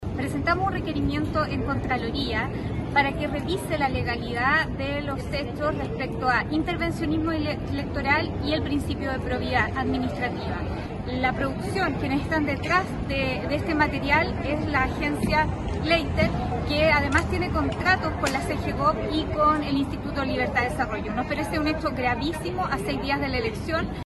En Apruebo Dignidad, la diputada frenteamplista Marcela Sandoval, quien presentó el requerimiento en Contraloría, explicó las razones de este recurso y dijo esperar que el órgano investigue la situación lo antes posible.